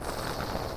snd_player_hypershot.wav